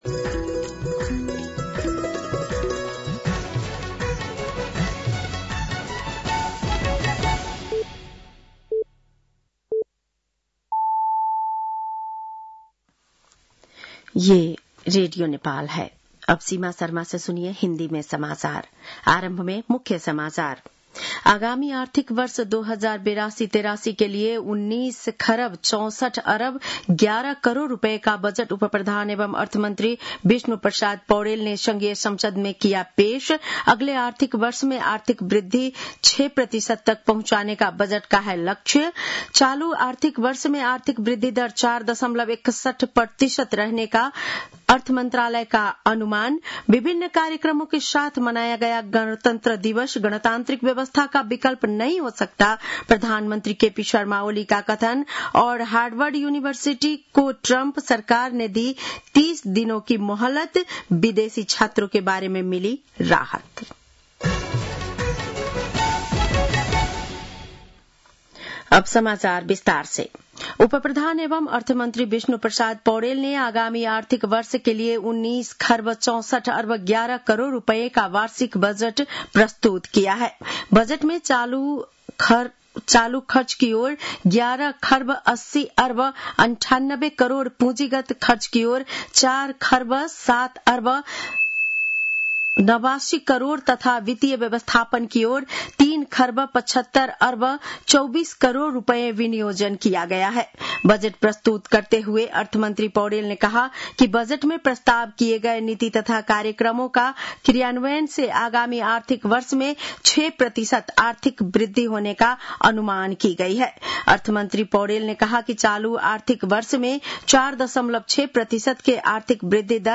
बेलुकी १० बजेको हिन्दी समाचार : १५ जेठ , २०८२
10-PM-Hindi-NEWS-1-4.mp3